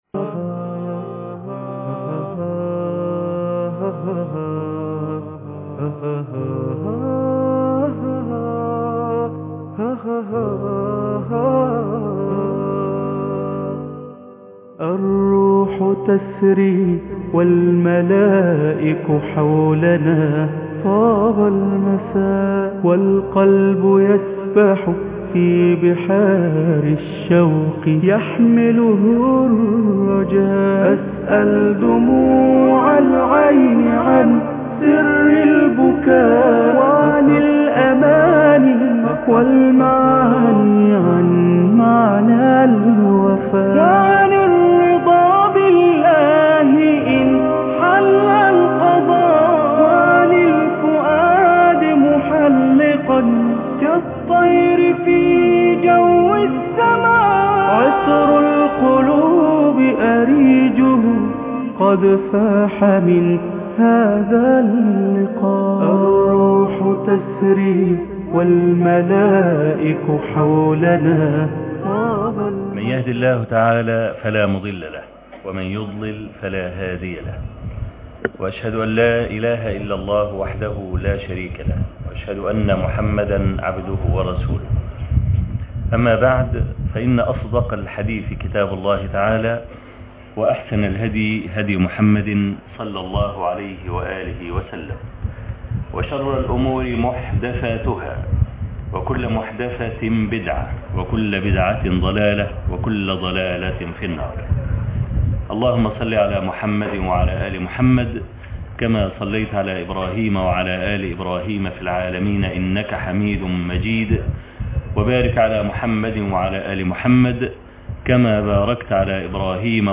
محاضرة الشيخ بجامعة كفر الشيخ - فضفضة - الشيخ أبو إسحاق الحويني